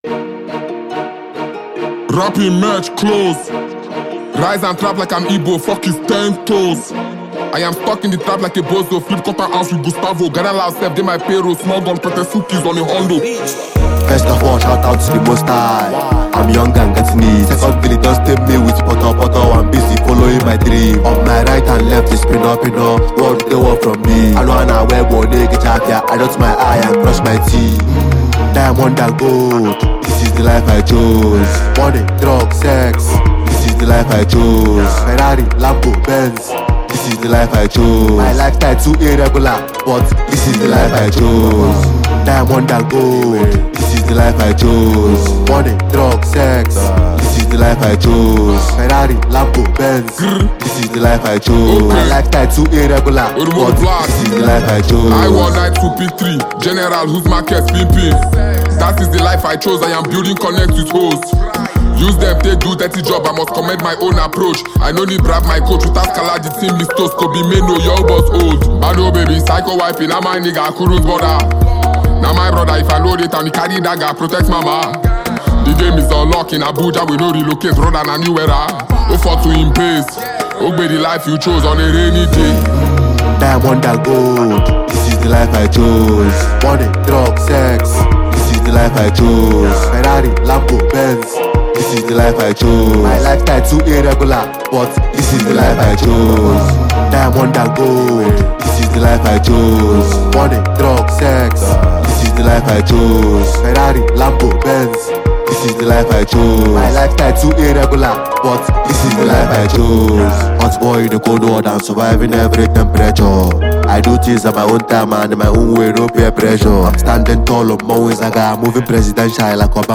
Veteran talented Nigerian  singer
mesmerizing melody